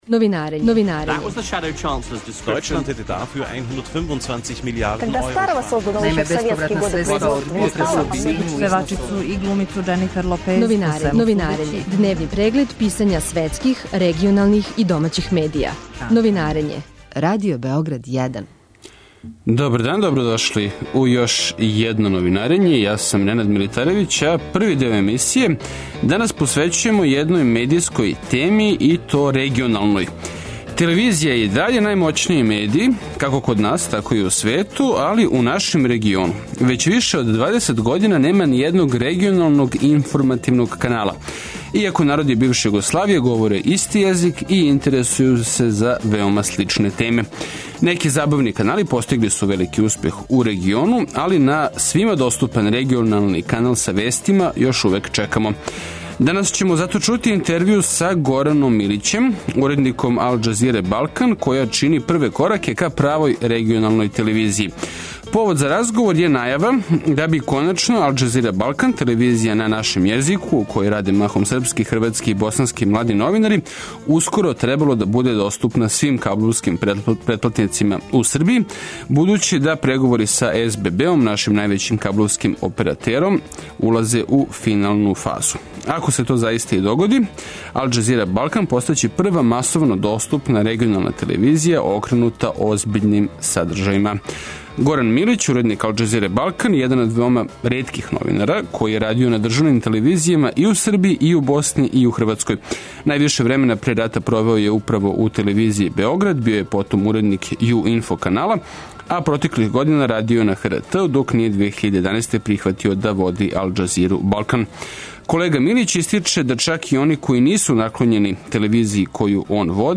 У нашем региону већ више од 20 година нема ни једног регионалног информативног канала, иако народи бивше Југославије говоре исти језик и интересују их сличне теме. За Новинарење говори Горан Милић, уредник Ал Џазире Балкан, телевизије која би ускоро требало да буде доступна свим кабловским претплатницима и у Србији, будући да су преговори са SBB-ом, нашим највећим кабловским оператером, у финалној фази.